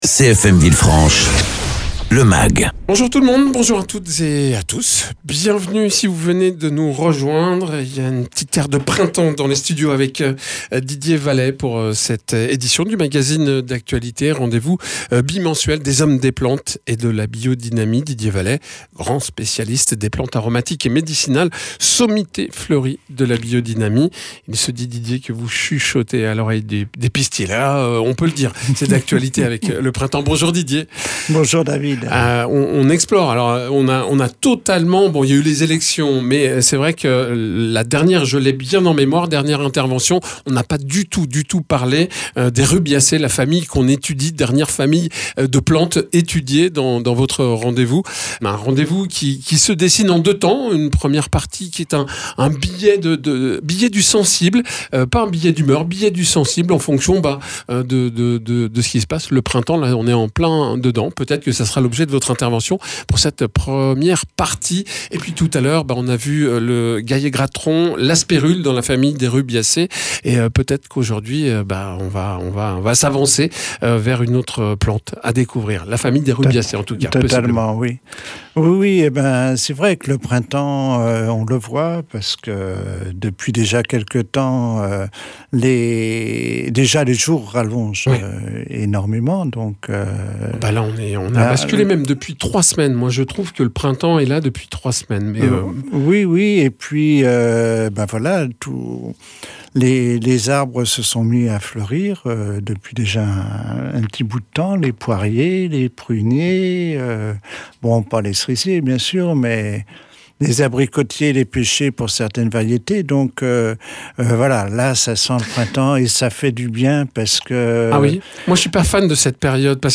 paysan spécialisé dans les plantes aromatiques et médicinales et la biodynamie.